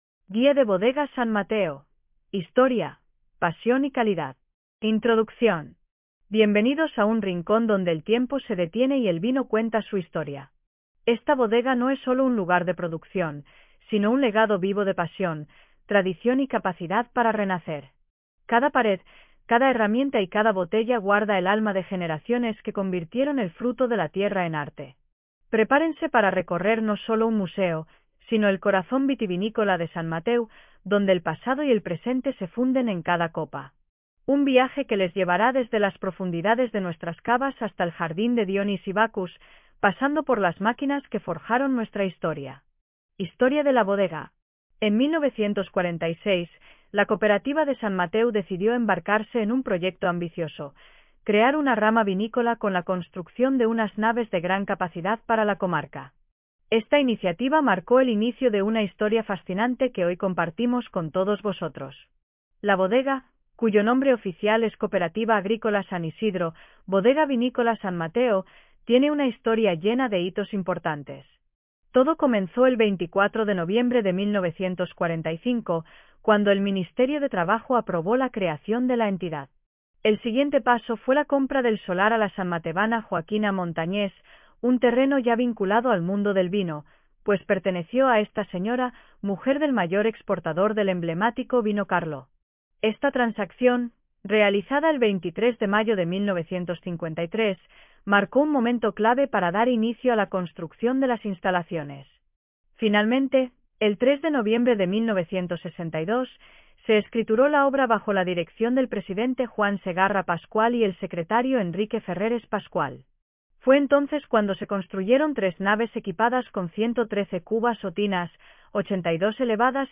AUDIOGUIA
Ahora puedes descubrir los secretos mejor guardados de nuestra historia vinícola desde cualquier lugar y en cualquier hora. Nuestra audioguía inmersiva te transportará por los rincones más emblemáticos de la bodega, con voces que te narrarán como si estuvieras.